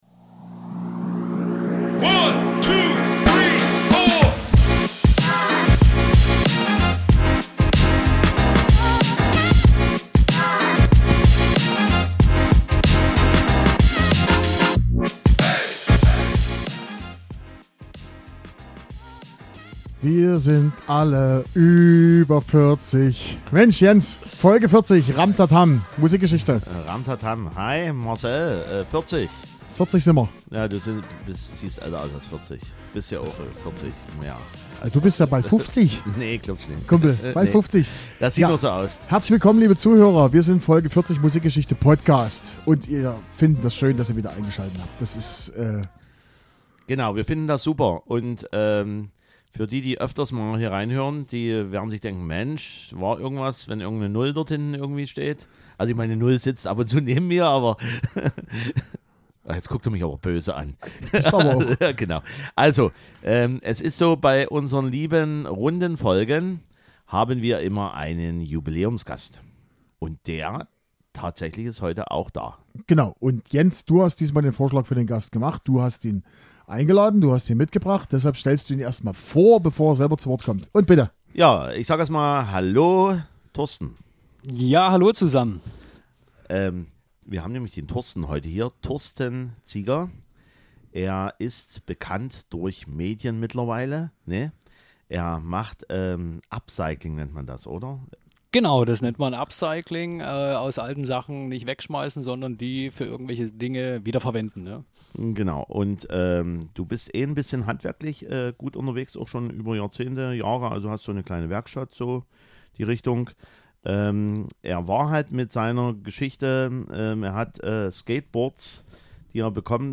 Außerdem endlich das Gewinnspiel aus Folge 36. Und es gab Wodka, und wir haben Schlager-Musik, gepaart mit Brit-Pop.